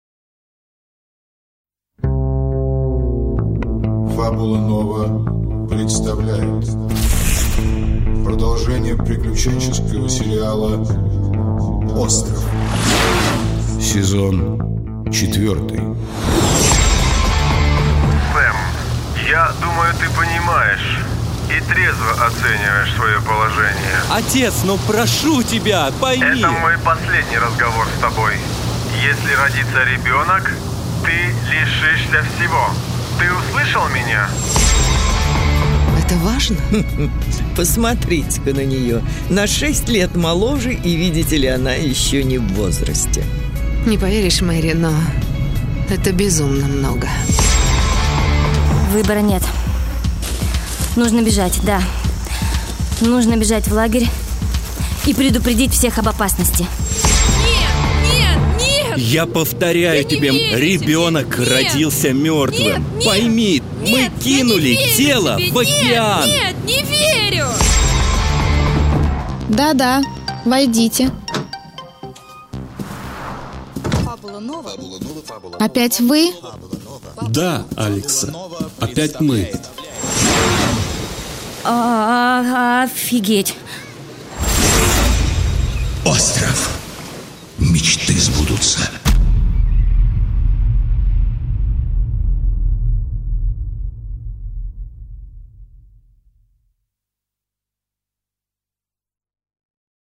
Аудиокнига Остров. Сорок лет ожидания | Библиотека аудиокниг